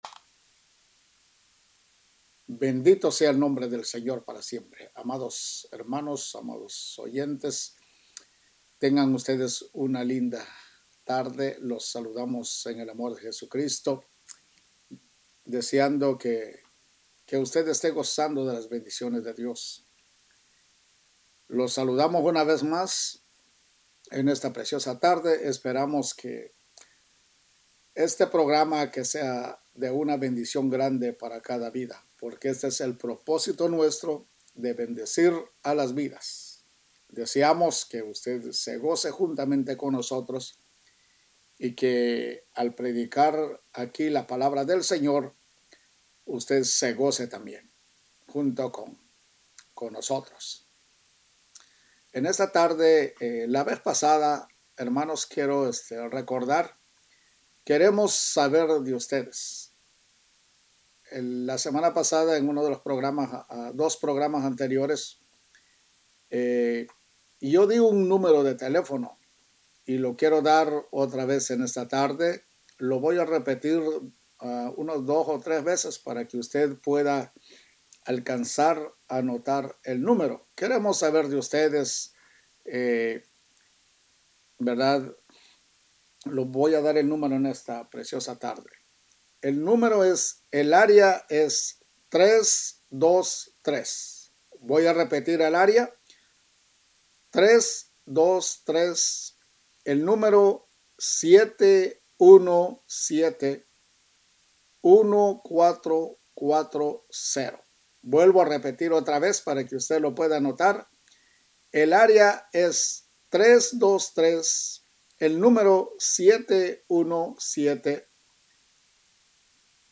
QUE SIGNIFICA CREER EN LA BIBLIA PARTE 1 PREDICA #4
QUE-SIGNIFICA-CREER-EN-BIBLIA-PARTE-1-PREDICA-4mp3.mp3